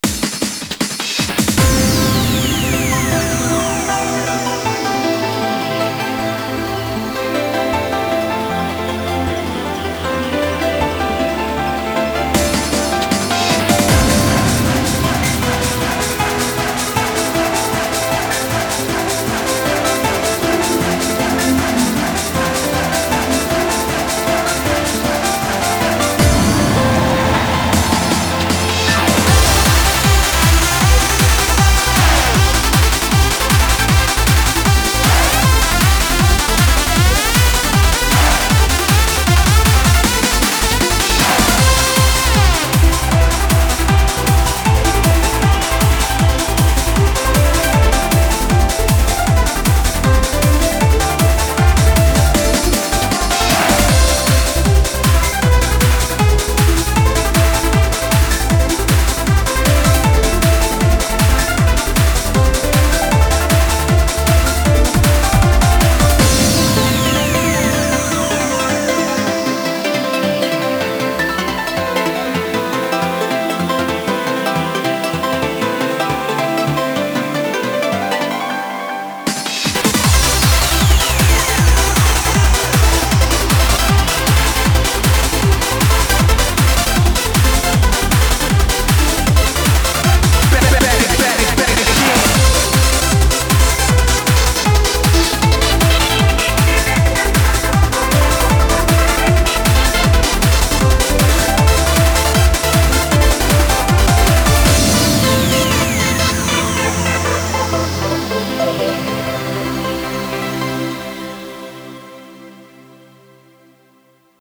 BPM156
Audio QualityPerfect (High Quality)
Comentarios[TRANCE]